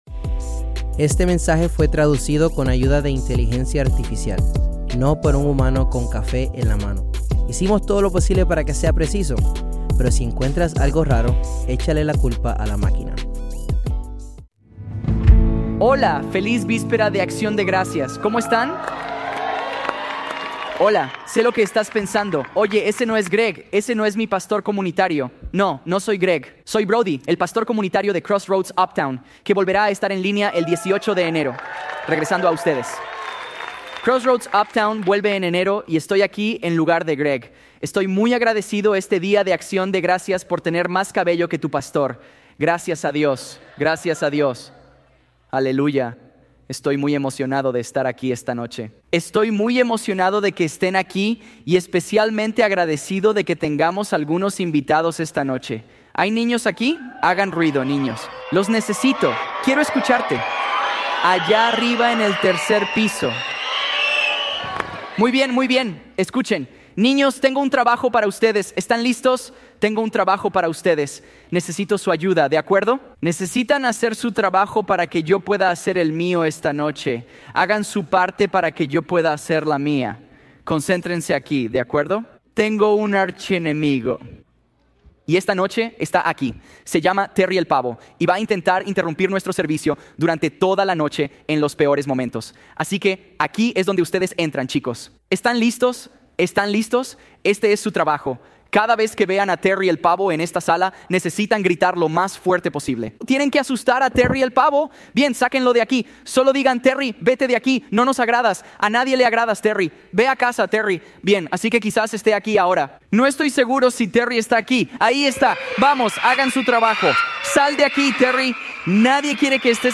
Grabado en vivo en Crossroads Church en Cincinnati, Ohio.